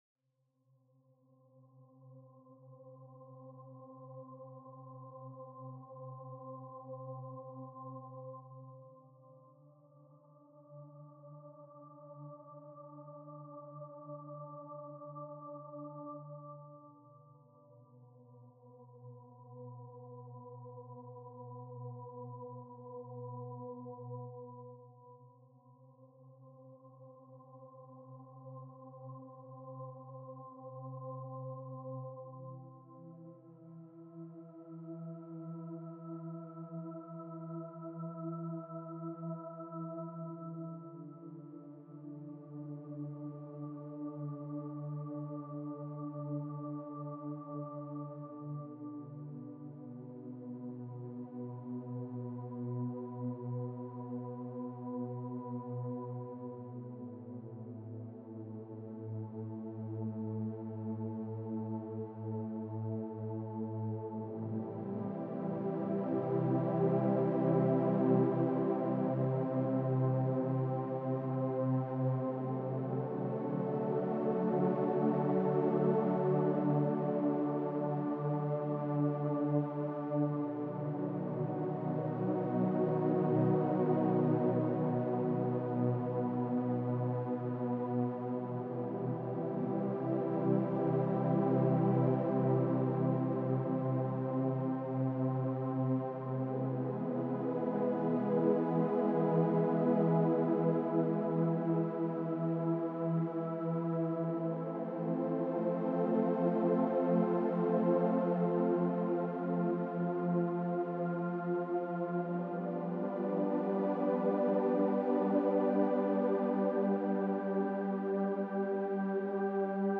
entspannende Klänge